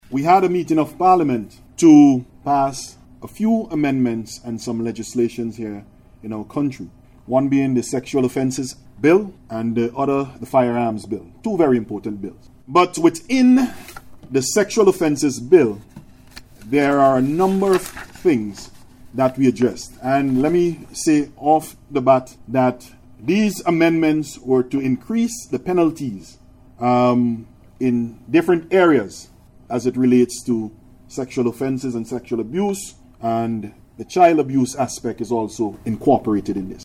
While delivering remarks and officially declaring the month of activities open, Minister of National Mobilisation, Dr. Orando Brewster said the Government remains committed to addressing the issue of Child abuse across the country evidenced by the number of bills being dealt with at the level of Parliament.